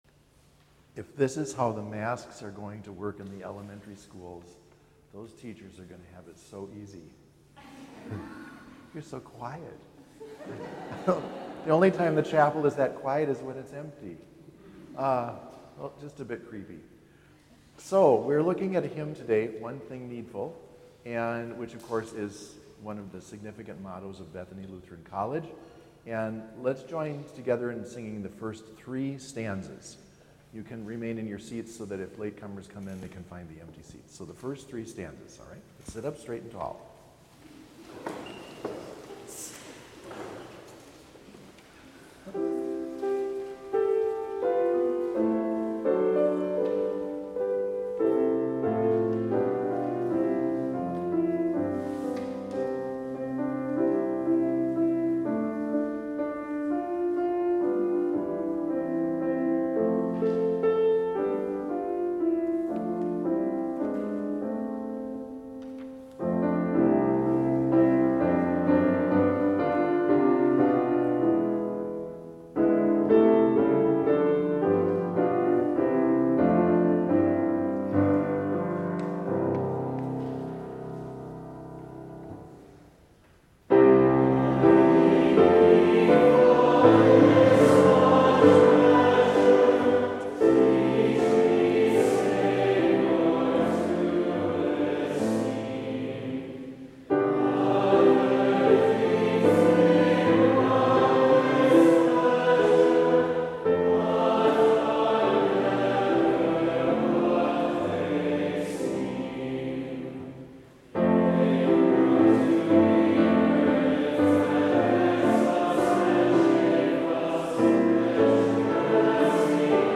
Complete service audio for Chapel - August 28, 2020